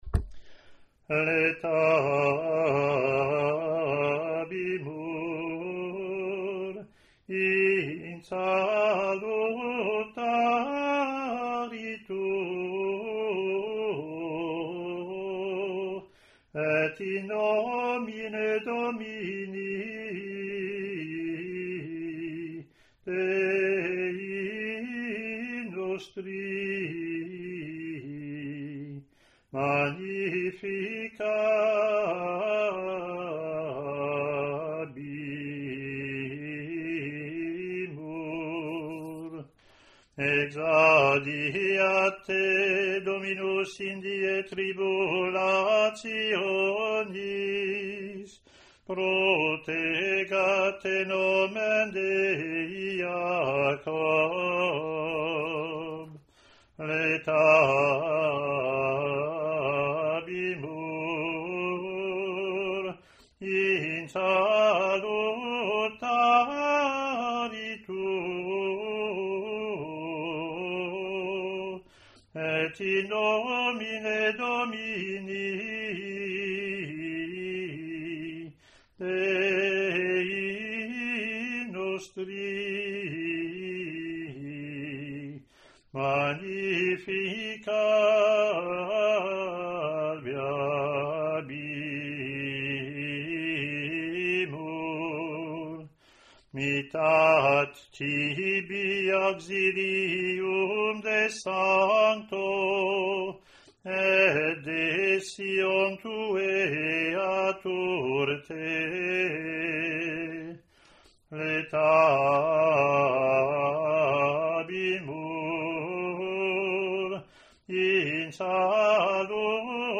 Latin antiphon+verses )
ot02-comm-w-verses-gm.mp3